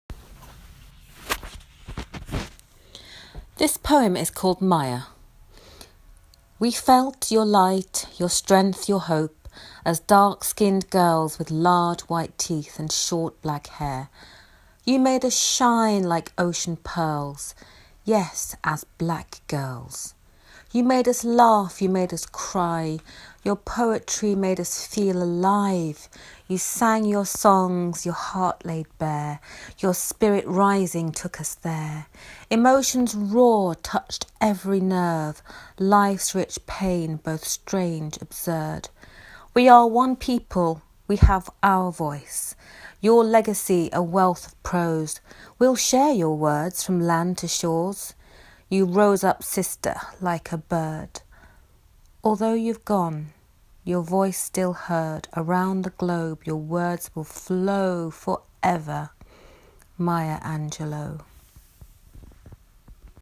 I wrote this, as a tribute to one of the most influential black women writers in recent history, and one of my favourite poets – Maya Angelou. I’ve recorded an audio clip of me reading the poem for your additional enjoyment! ad it.